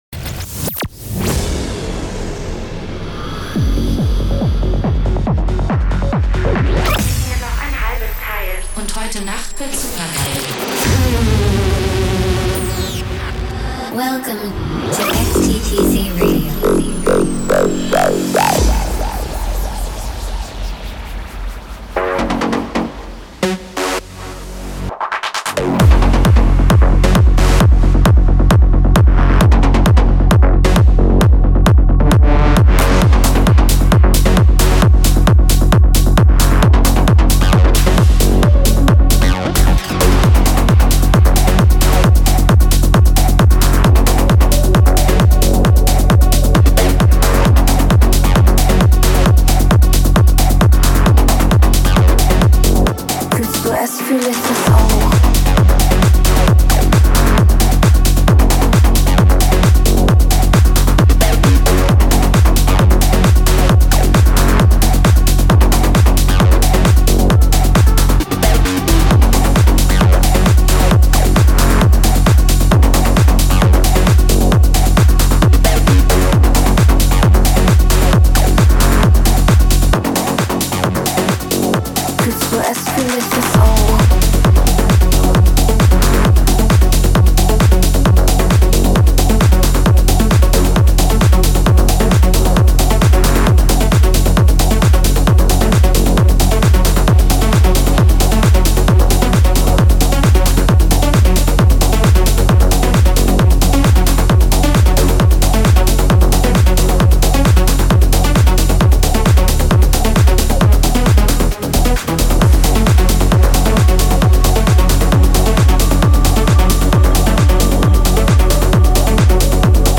known for his techno, trance and rave tracks